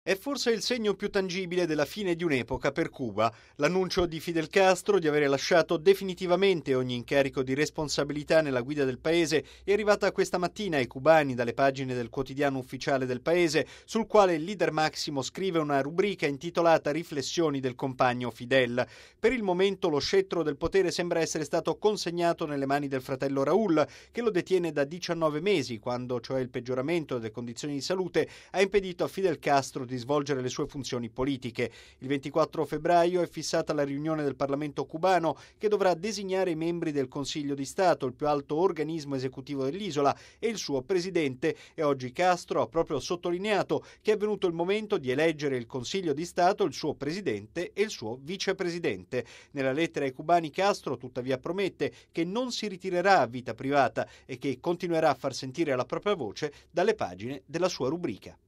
Fidel Castro lascia la carica di presidente di Cuba dopo 49 anni di potere. L’annuncio è stato dato dallo stesso Castro attraverso le colonne del quotidiano ufficiale Granma. Il servizio